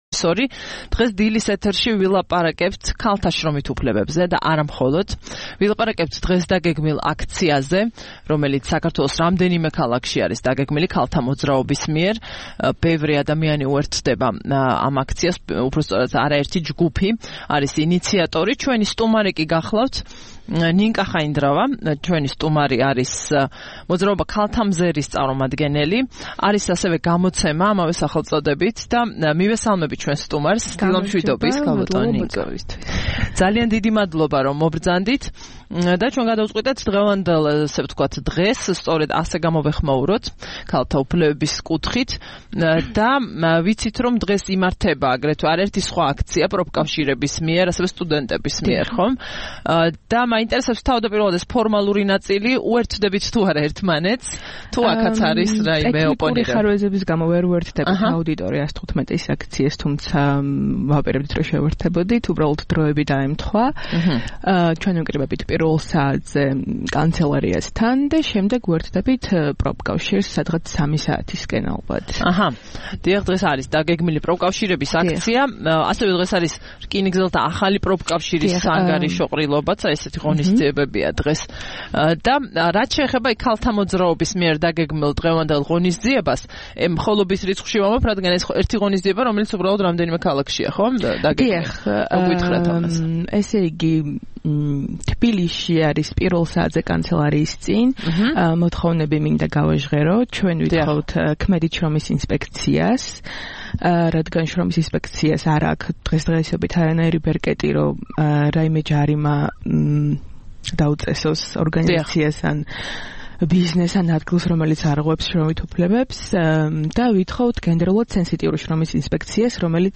1 მაისს რადიო თავისუფლების "დილის საუბრების" სტუმარი იყო